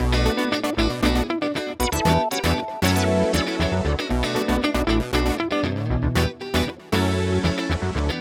11 Backing PT2.wav